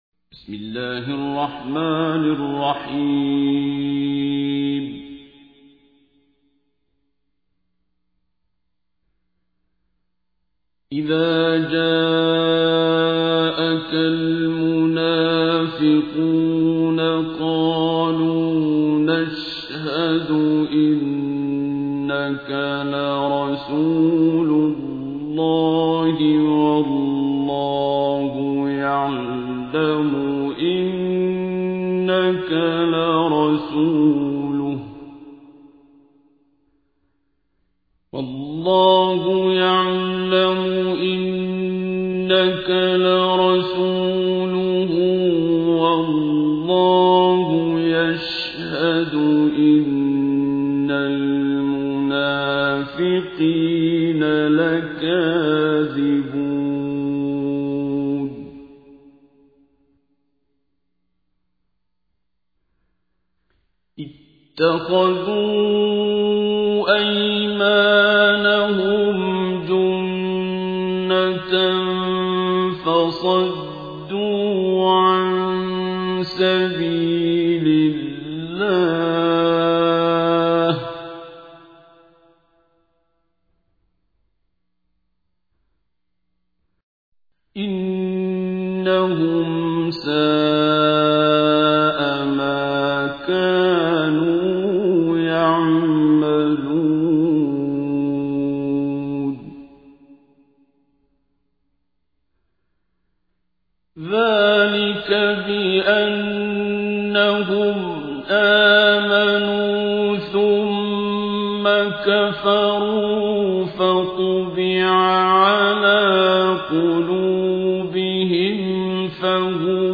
تحميل : 63. سورة المنافقون / القارئ عبد الباسط عبد الصمد / القرآن الكريم / موقع يا حسين